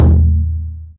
～【効果音】～
ドンッ（始め！）